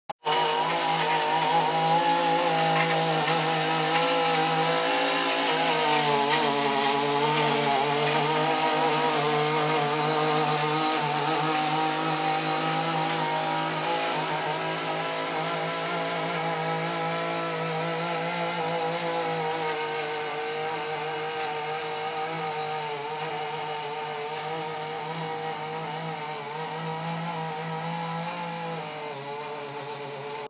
Edger
edger-1.m4a